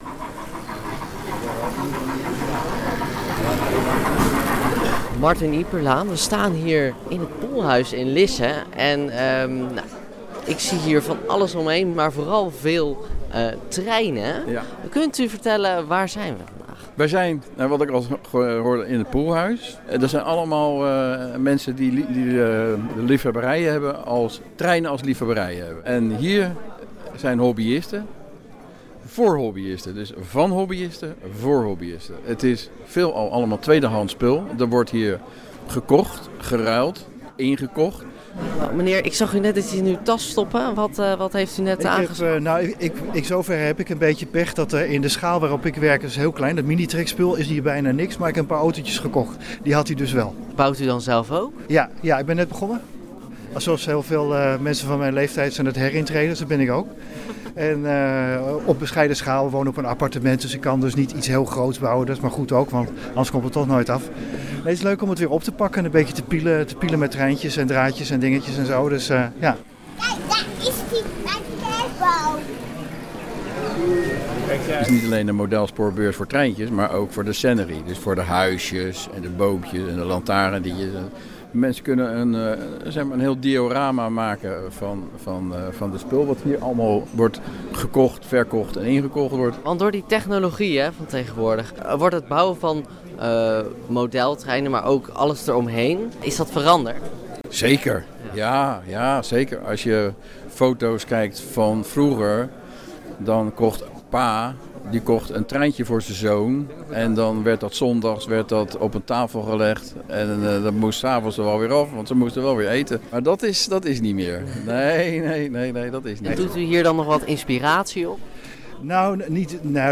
Lisse – Rondrijdende treinen, miniatuur locomotieven en bakken vol tweedehands lichten, poppetjes en autootjes. Voor de zeventiende keer staat het Lissese Poelhuys in het teken van de Modelspoorbeurs.